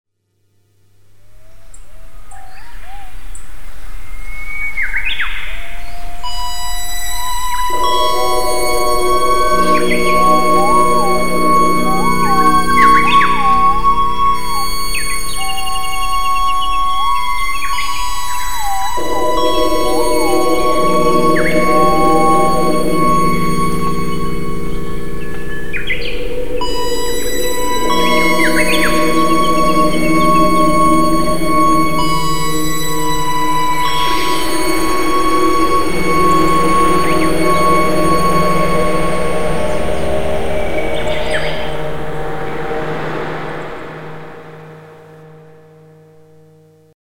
ＳＹ２２サウンド
例えば風の音を出す場合、ただ指一本でサンプリングされた風の音を出すだけではなく、自分のハーモニーを奏でられます。 そしてＶＥＣＴＯＲジョイスティックによる４音色の劇的ミックスバランスの変化で音がクロスフェードして入れ替わり出現します。